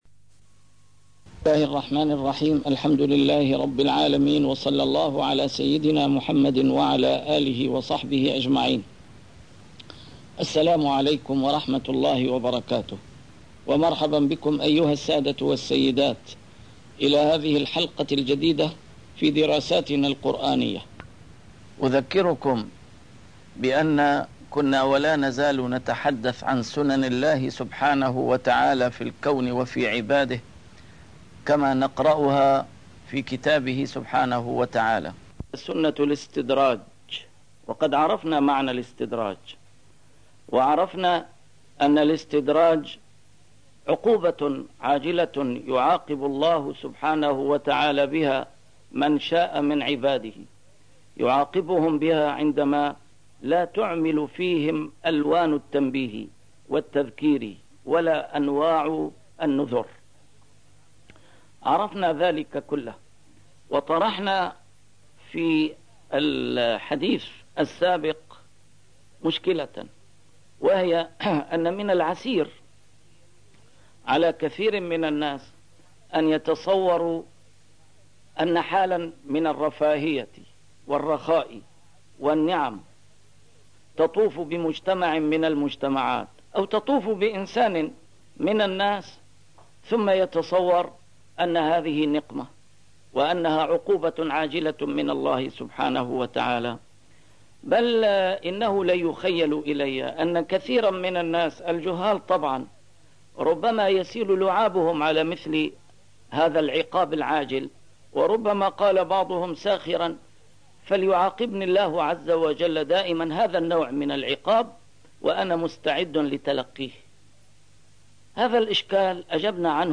A MARTYR SCHOLAR: IMAM MUHAMMAD SAEED RAMADAN AL-BOUTI - الدروس العلمية - دراسات قرآنية - سنن الله سبحانه وتعالى في الكون والعباد في قرآنه وخطابه لنا